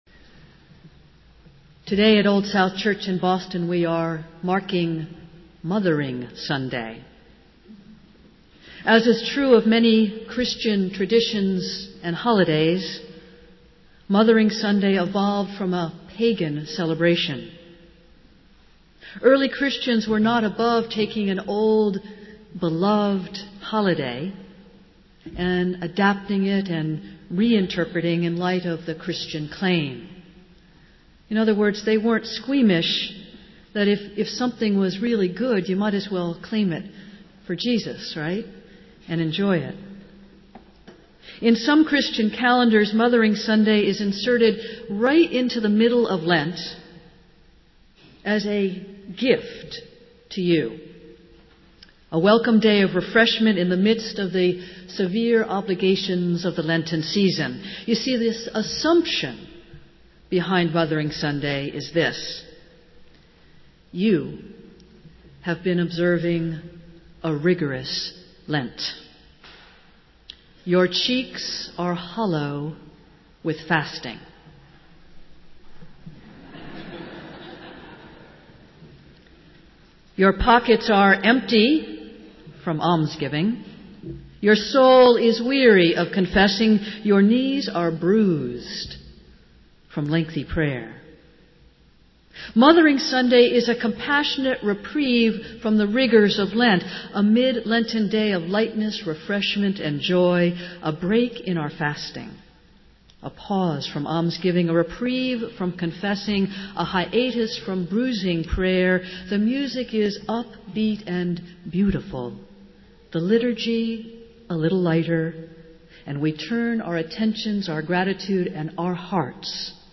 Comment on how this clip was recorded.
Festival Worship - Fourth Sunday of Lent